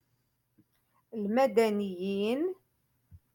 Moroccan Dialect- Rotation Five-Lesson sixty Three